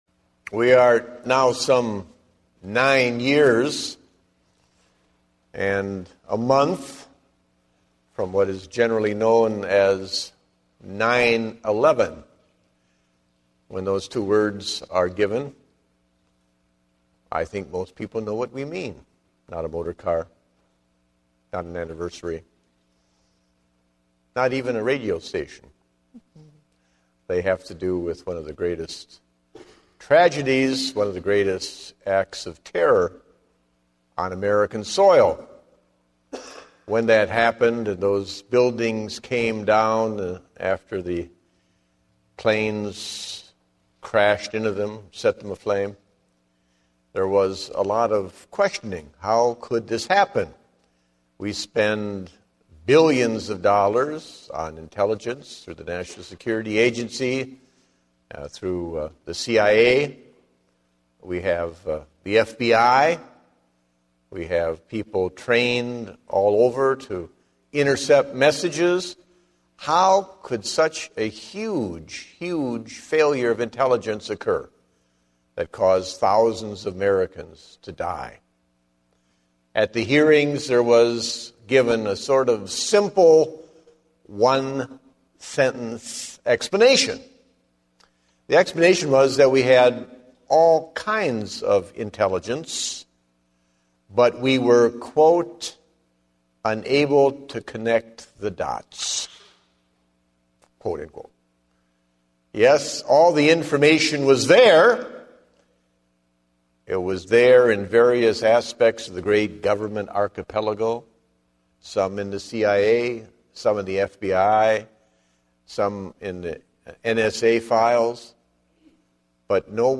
Date: October 10, 2010 (Evening Service)